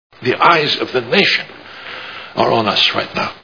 Minority Report Movie Sound Bites